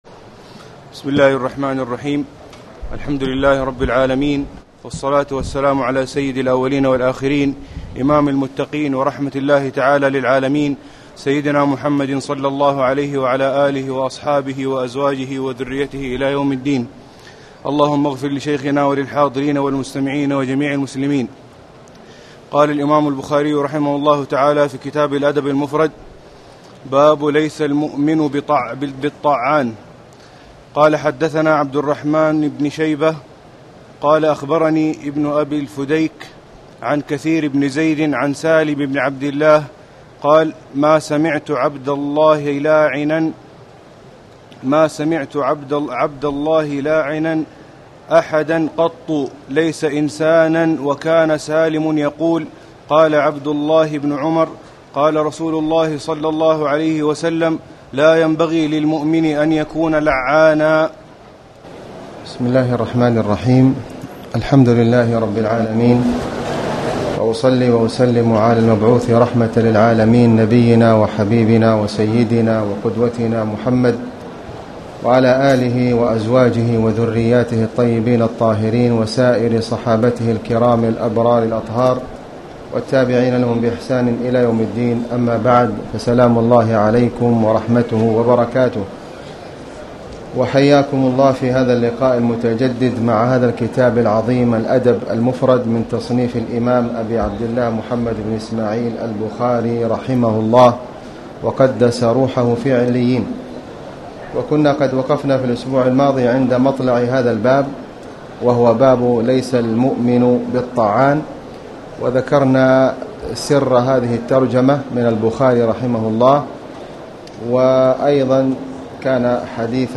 تاريخ النشر ٢ ربيع الأول ١٤٣٩ هـ المكان: المسجد الحرام الشيخ: فضيلة الشيخ د. خالد بن علي الغامدي فضيلة الشيخ د. خالد بن علي الغامدي باب اللعن